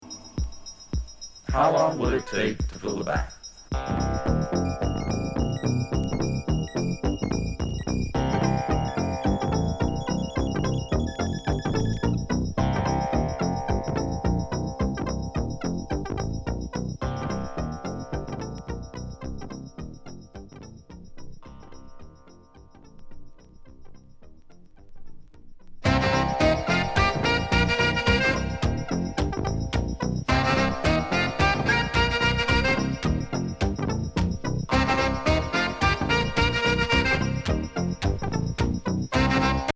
脱ギズモ熱後のひねくれエレクトロニクス・ポップにオカルティックな世界観全開のラップ。